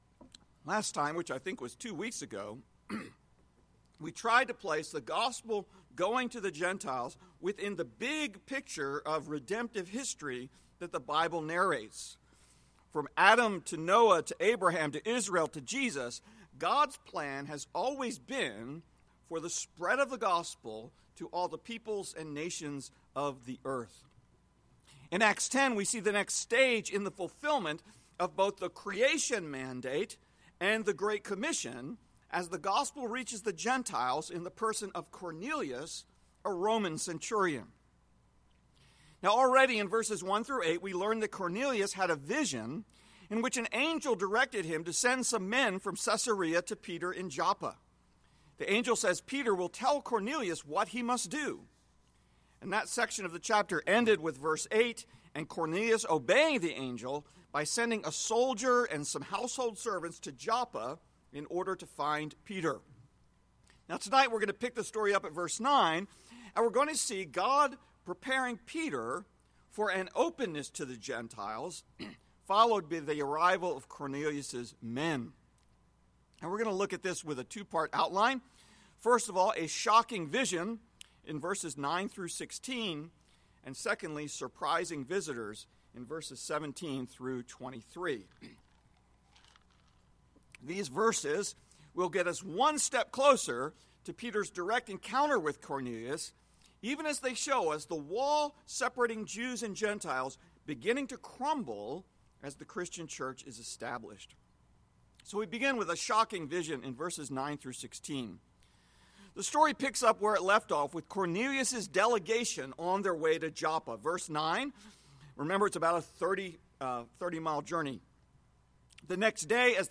Message Text: Acts 10:9-23a